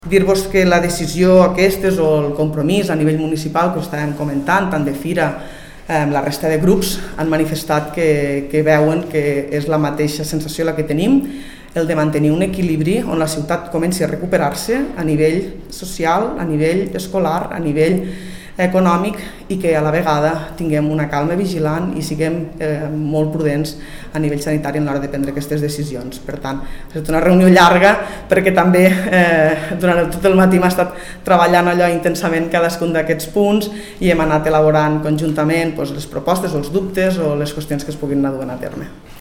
tall-de-veu-de-la-tinent-dalcalde-jordina-freixanet-sobre-la-reunio-amb-els-i-les-portaveus-dels-grups-municipals